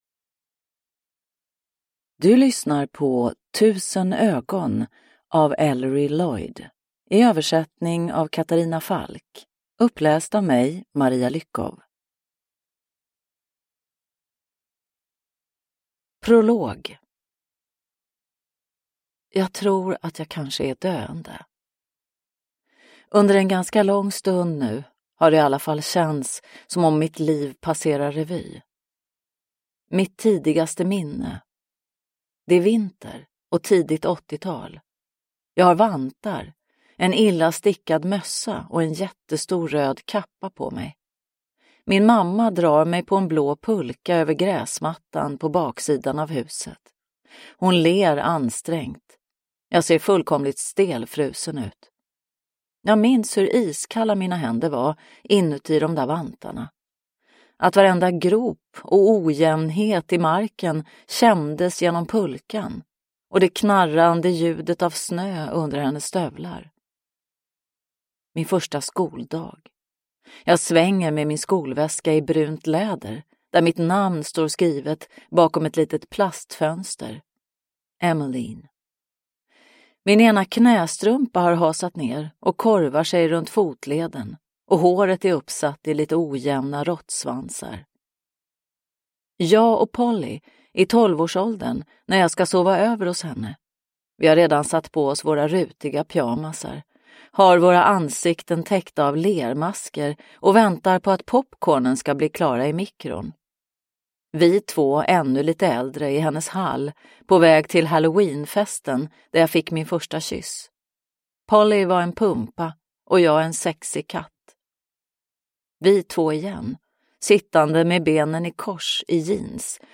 Tusen ögon – Ljudbok – Laddas ner